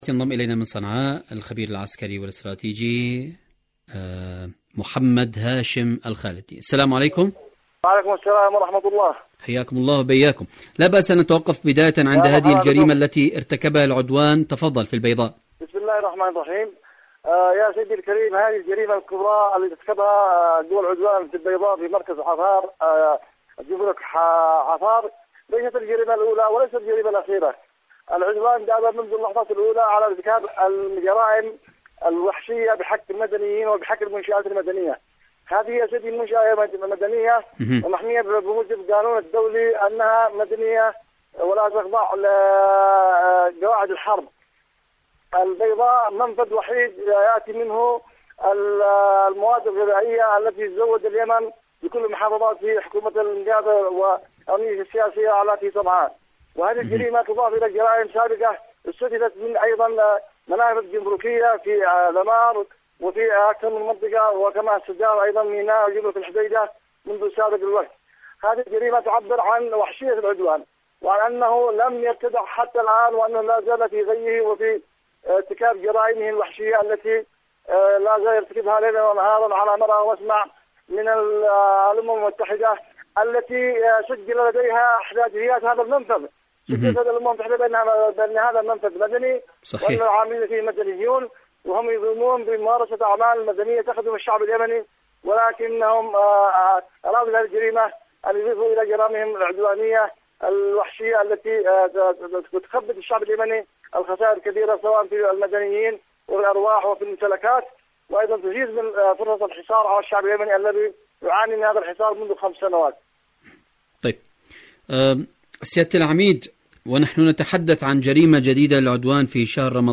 إذاعة طهران-اليمن التصدي والتحدي: مقابلات إذاعية